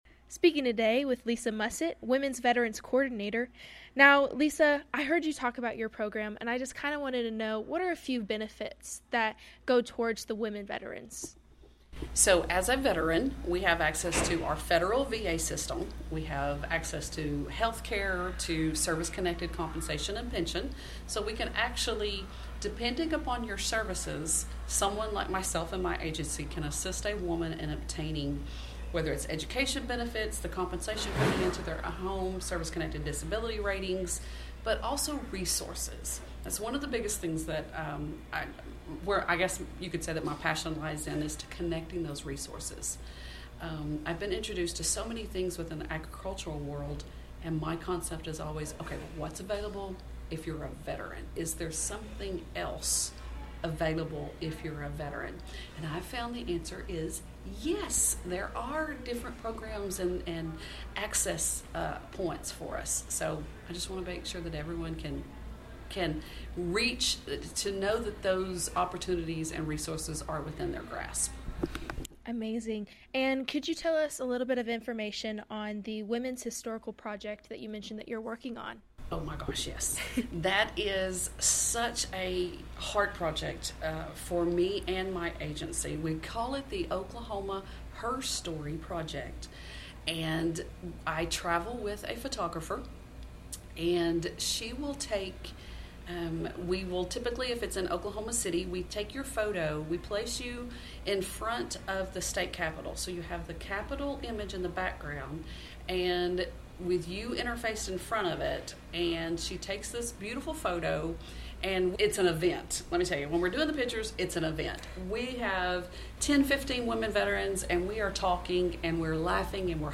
During the Women in Agriculture Conference